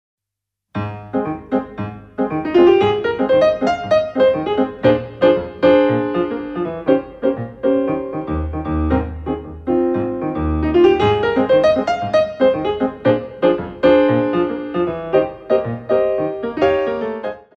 Petit allegro 2